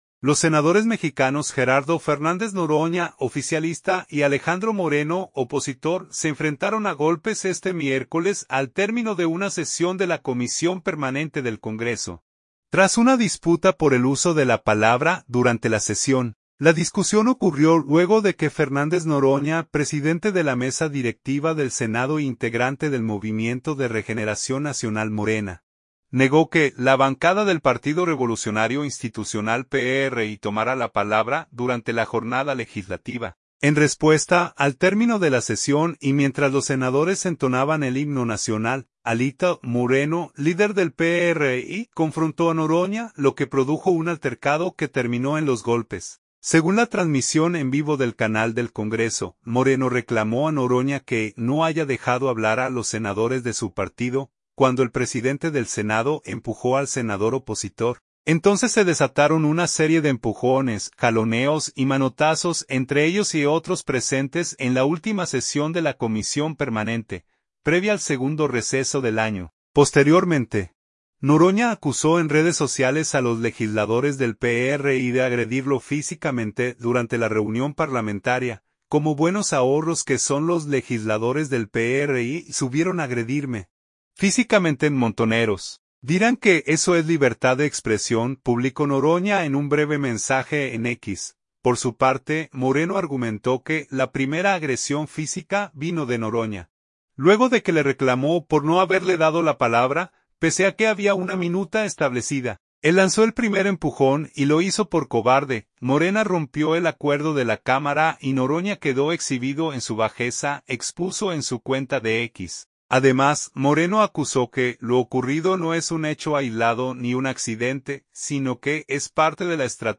En respuesta, al término de la sesión y mientras los senadores entonaban el himno nacional, ‘Alito’ Moreno, líder del PRI, confrontó a Noroña, lo que produjo un altercado que terminó en los golpes.
Según la transmisión en vivo del Canal del Congreso, Moreno reclamó a Noroña que no haya dejado hablar a los senadores de su partido, cuando el presidente del Senado empujó al senador opositor.
Entonces se desataron una serie de empujones, jaloneos y manotazos entre ellos y otros presentes en la última sesión de la comisión Permanente, previa al segundo receso del año.